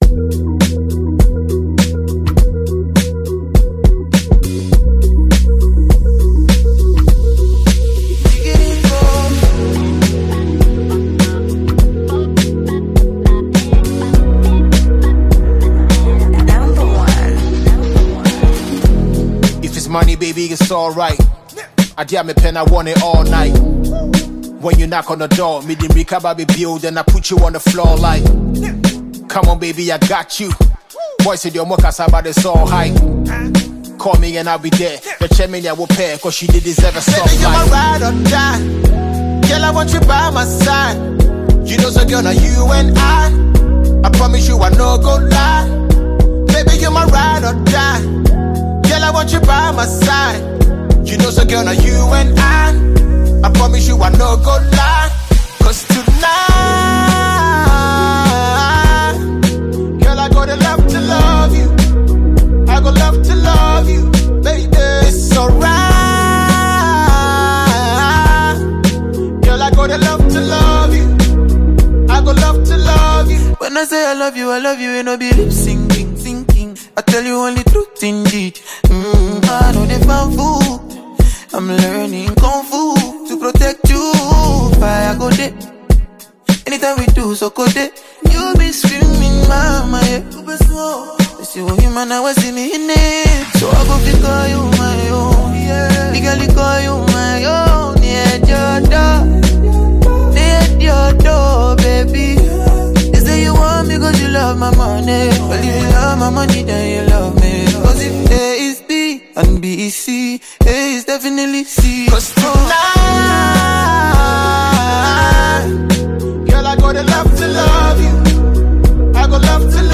• Genre: Hip-Hop / Afrobeat / Dancehall